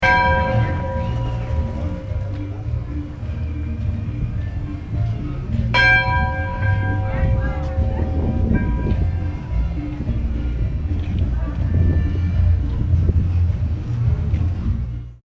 the bell playing on the upbeat
juarez-polka.mp3